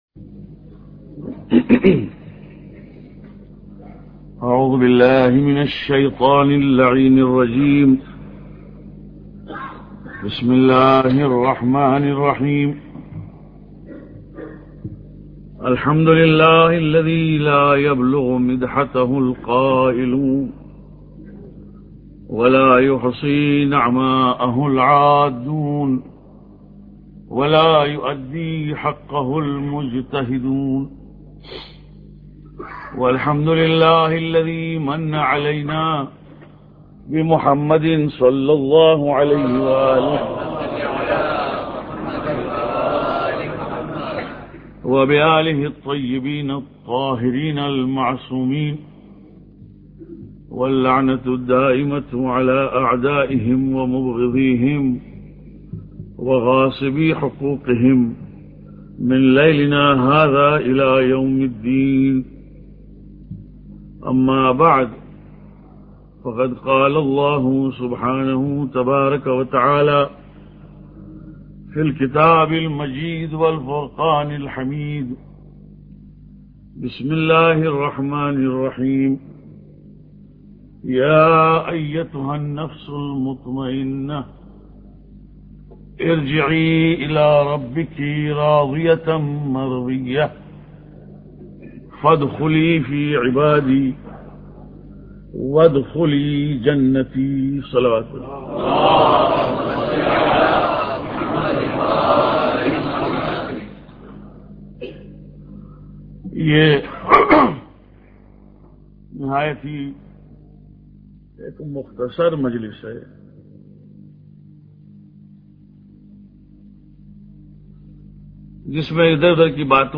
Lecture 18